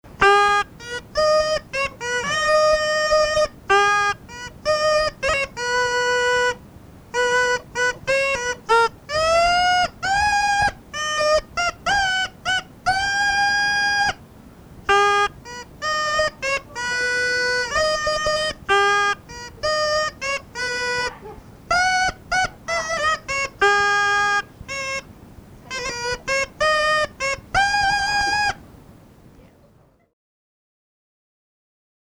Голоса уходящего века (Курское село Илёк) Ой, мороз, мороз (рожок, инструментальная версия)